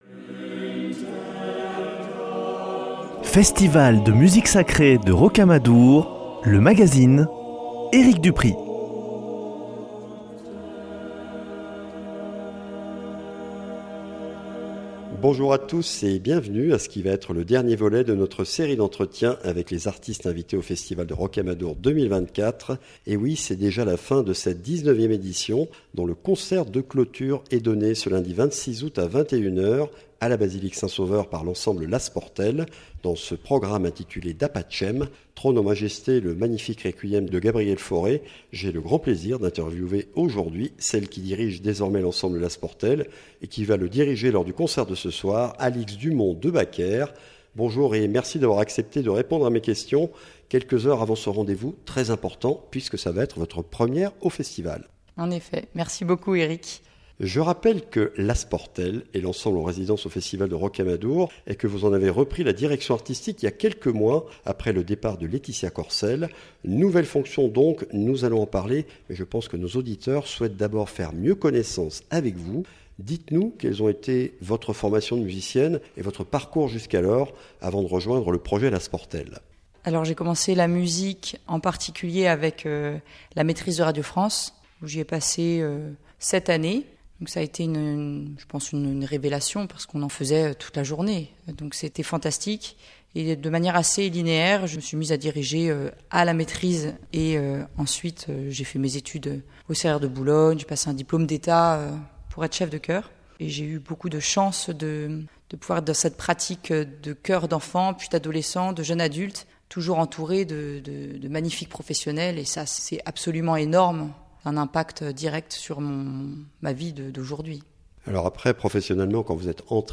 Chronique Rocamadour